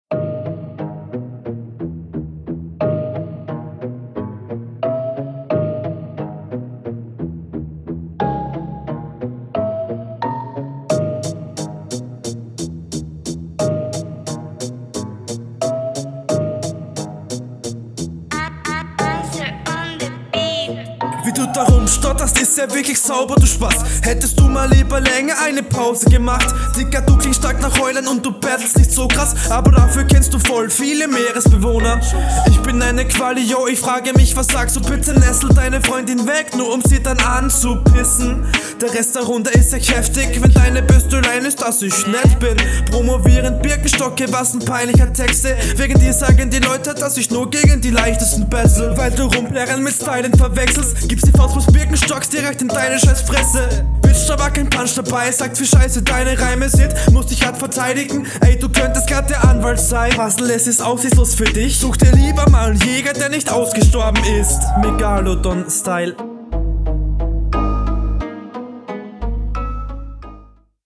Ziemlich guter Stimmeinsatz, die Delivery ist solide, teilweise gut betont, teilweise zu gehetzt, der Flow …
bei dir ist der Mix besser und du bist auch viel angenehmer auf dem Beat.